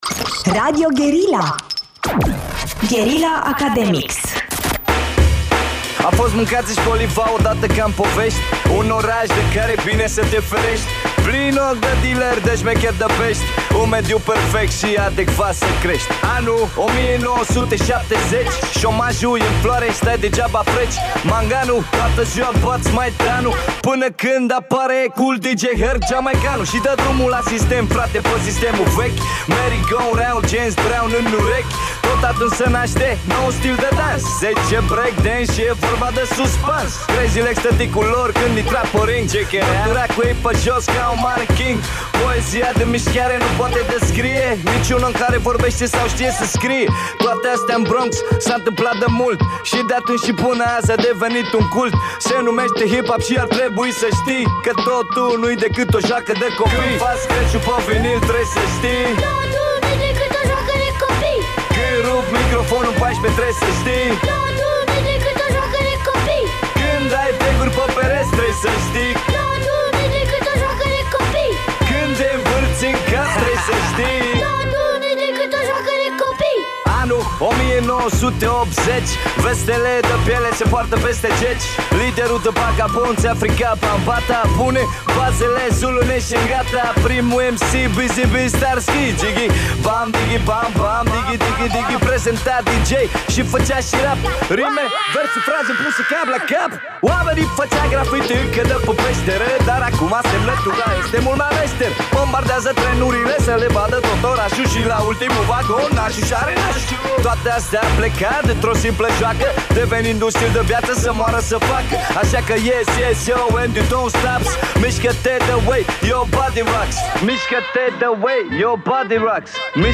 Academics este emisiunea de la Radio Guerrilla ce îi are drept gazde pe elevii din primele sezoane Uman Real, care acum au crescut și au devenit studenți.